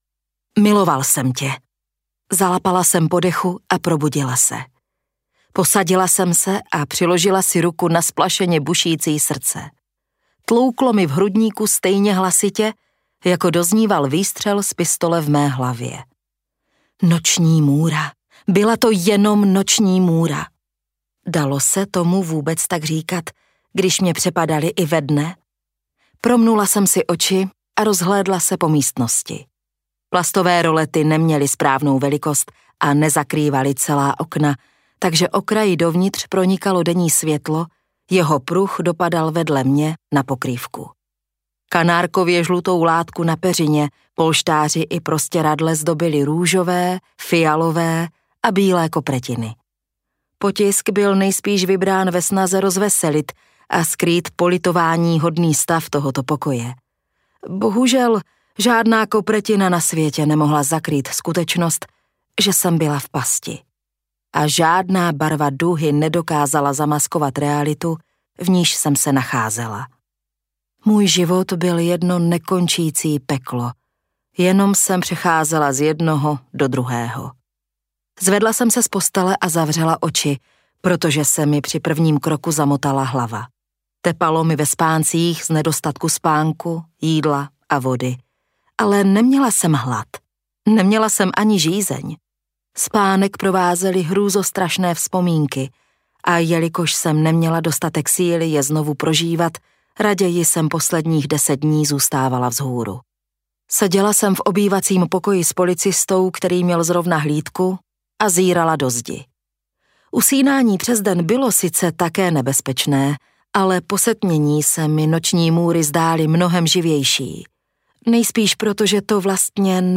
MP3 Audiobook